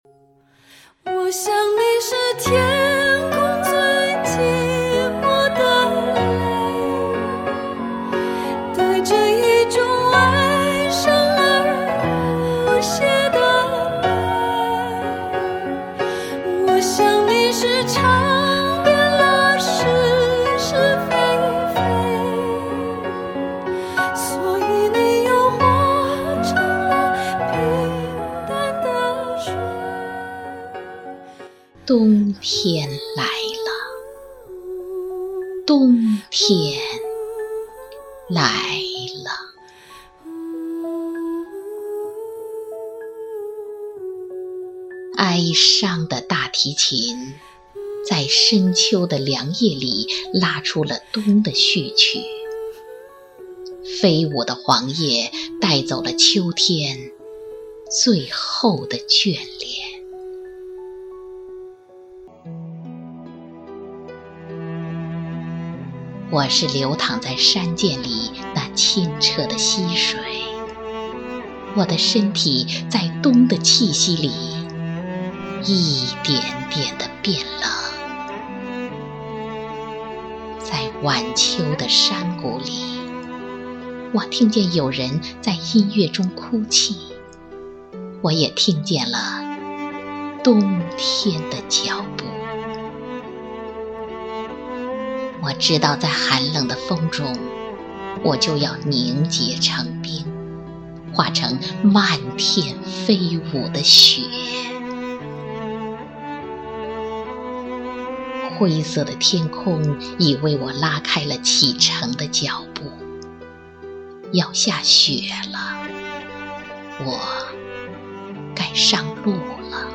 配乐朗诵: 冬天来了 激动社区，陪你一起慢慢变老！